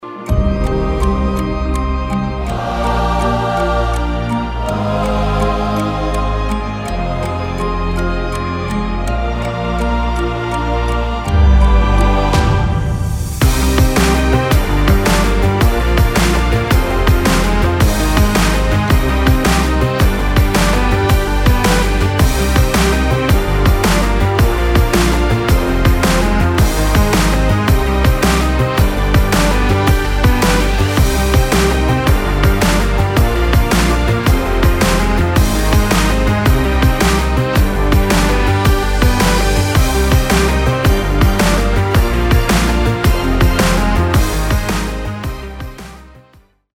Musical
Instrumental , orchestral , backing track